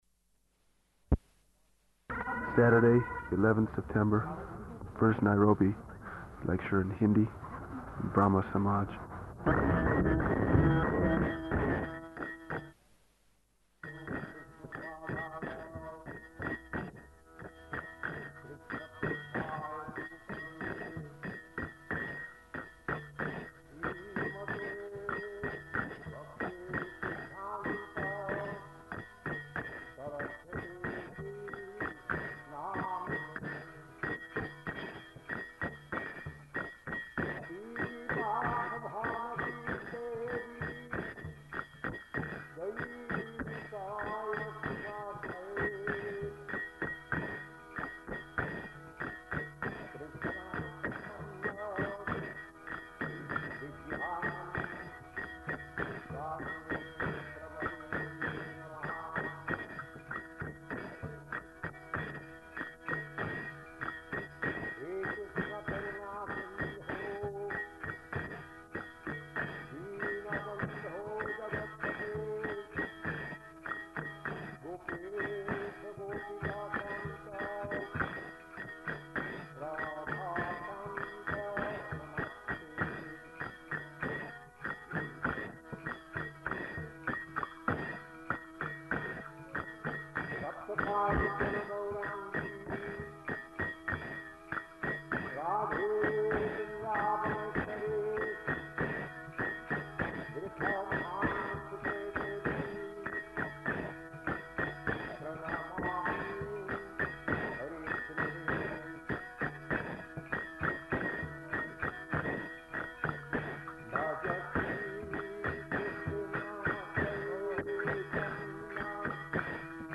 Lecture in Hindi
Lecture in Hindi --:-- --:-- Type: Lectures and Addresses Dated: September 11th 1971 Location: London Audio file: 710911LE-NAIROBI.mp3 Devotee: Saturday 11th September. First Nairobi lecture in Hindi.